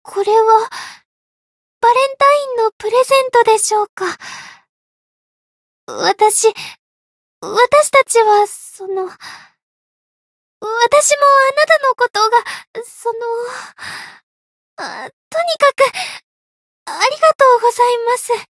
灵魂潮汐-伊汐尔-情人节（送礼语音）.ogg